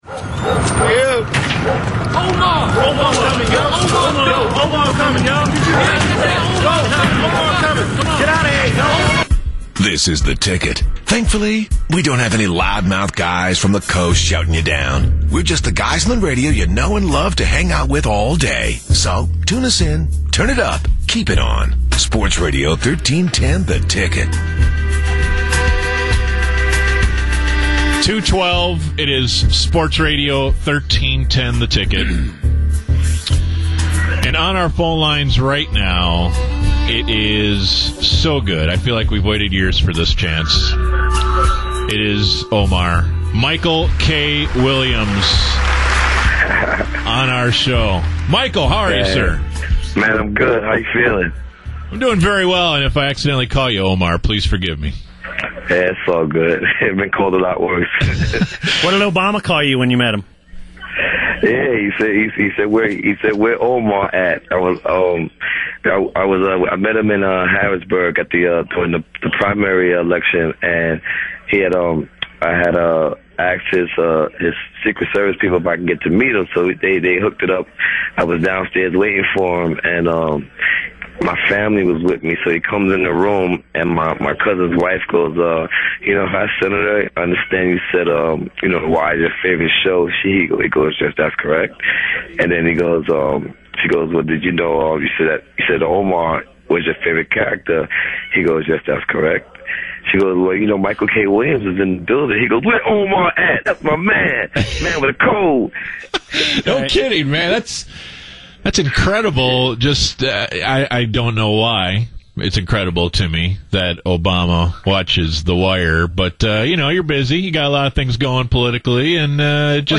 BaD Radio Interviews Michael Williams (Omar from The Wire)
BaD Radio interviews Michael K Williams, AKA Omar from The Wire.